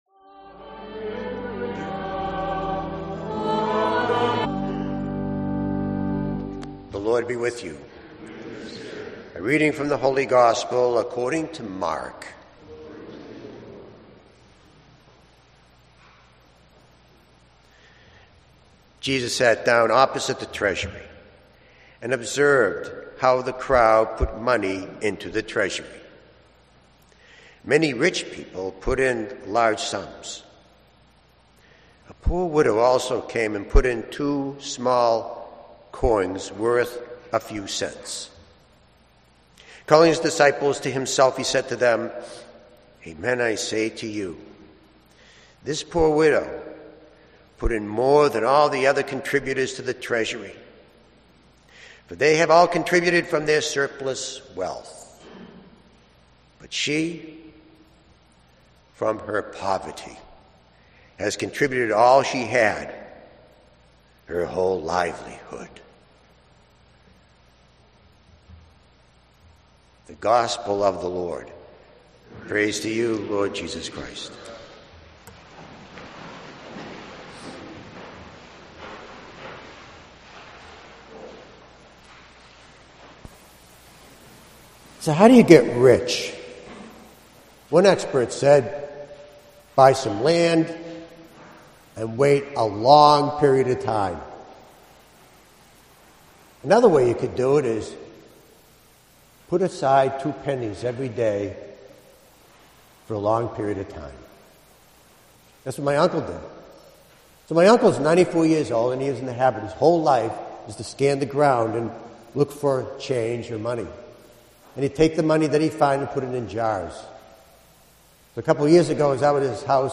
Homilies and Podcasts - St. Clement Eucharistic Shrine